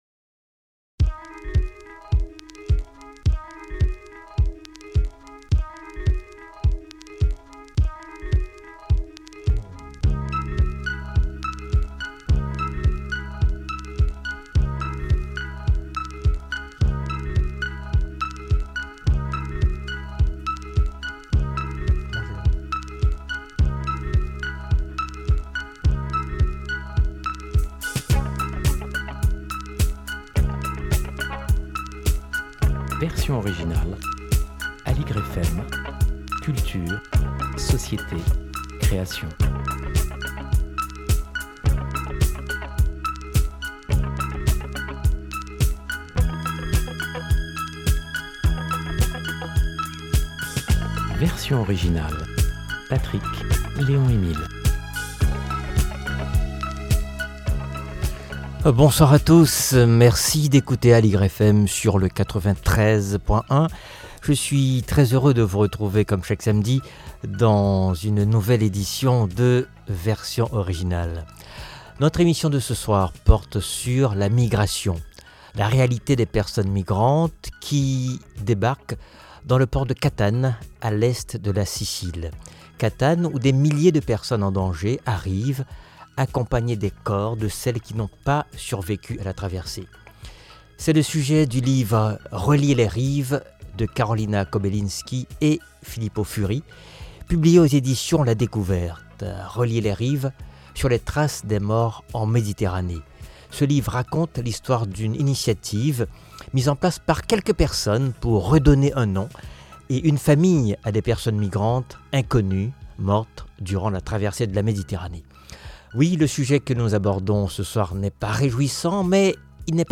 Sur le port de Catalane, en Sicile, une équipe du comité la Croix-Rouge prend en charge les centaines de migrants en péril qui débarquent, accompagnés des corps de ceux et celles qui n'ont pas survécu à la traversée. Entretien avec les deux auteurs du livre